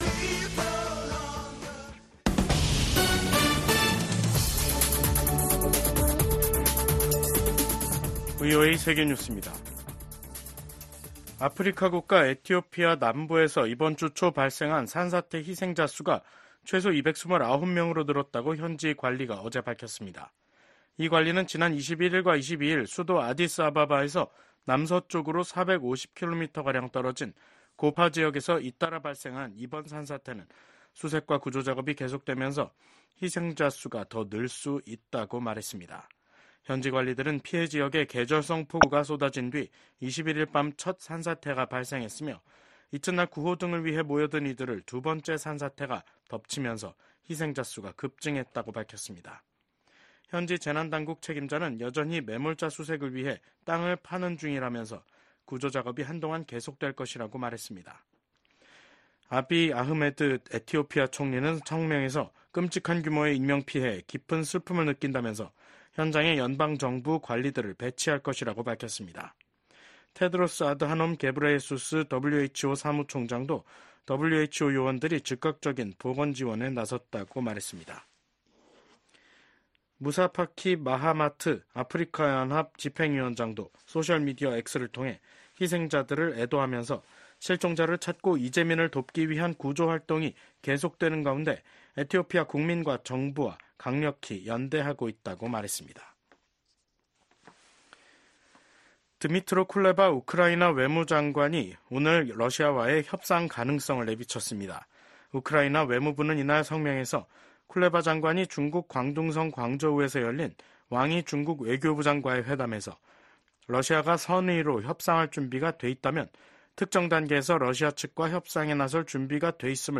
VOA 한국어 간판 뉴스 프로그램 '뉴스 투데이', 2024년 7월 24일 3부 방송입니다. 북한이 또 다시 쓰레기 풍선을 한국 쪽에 날려보냈습니다.